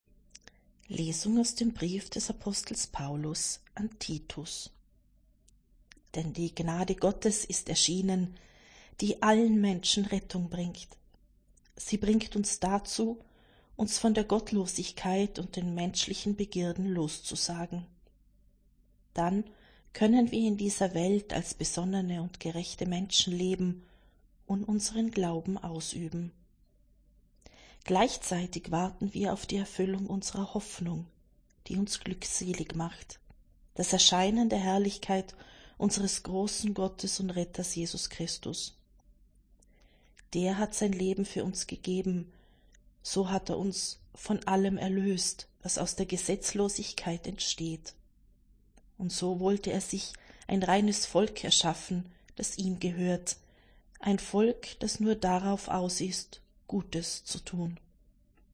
Wenn Sie den Text der 2. Lesung aus der Apostelgeschichte